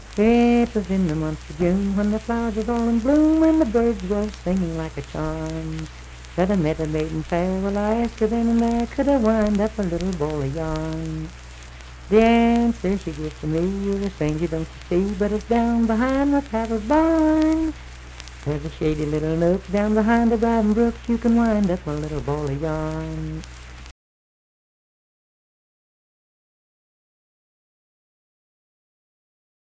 Unaccompanied vocal music performance
Braxton County, WV.
Bawdy Songs
Voice (sung)